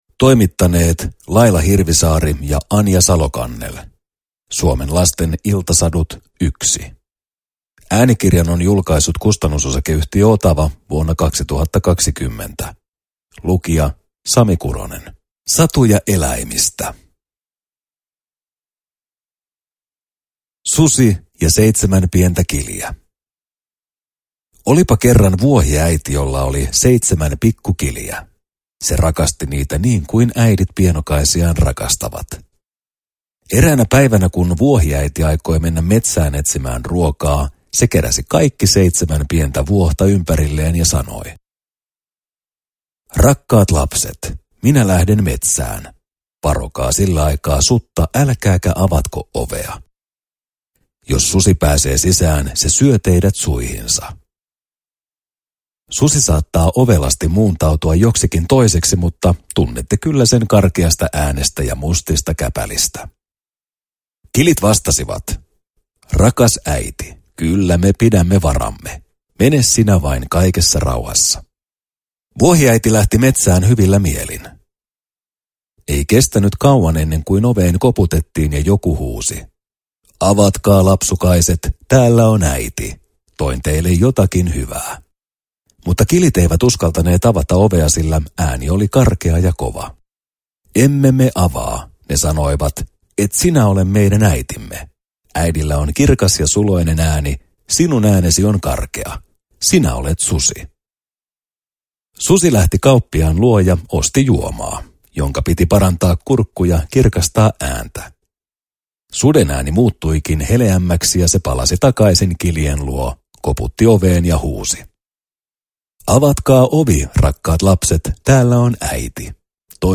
Suomen lasten iltasadut 1 – Ljudbok – Laddas ner